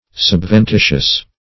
Subventitious \Sub`ven*ti"tious\